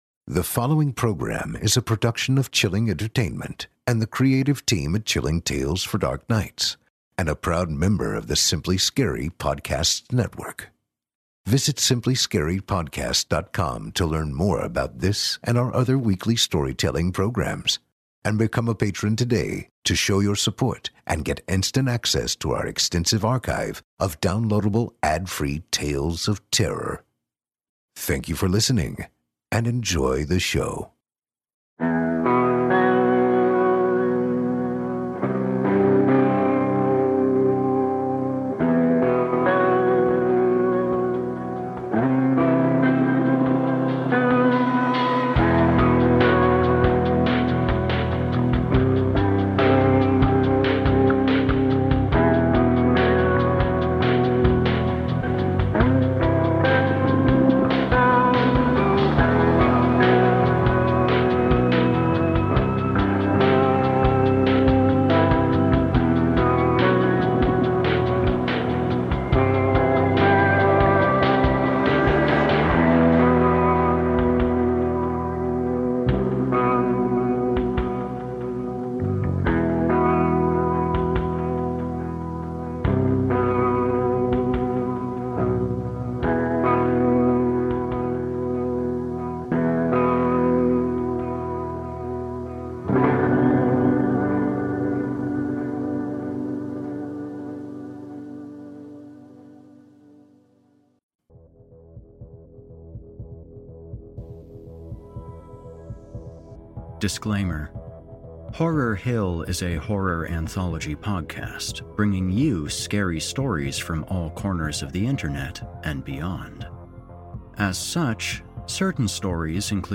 Well hello there, listeners, and welcome back to Horror Hill.